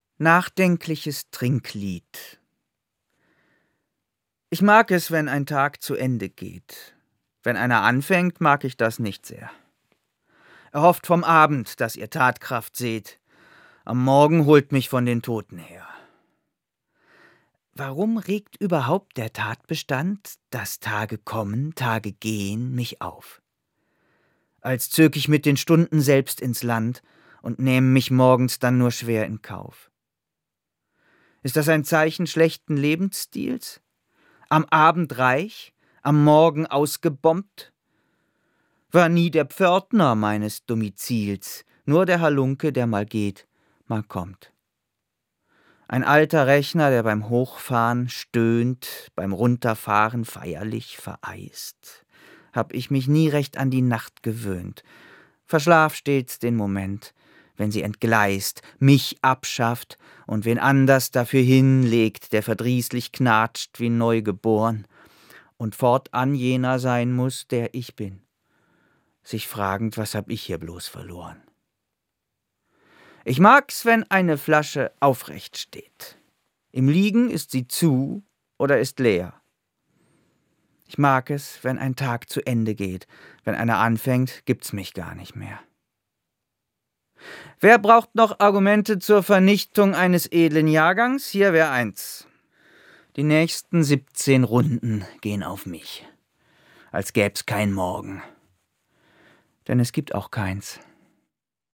Das radio3-Gedicht der Woche: Dichter von heute lesen radiophone Lyrik.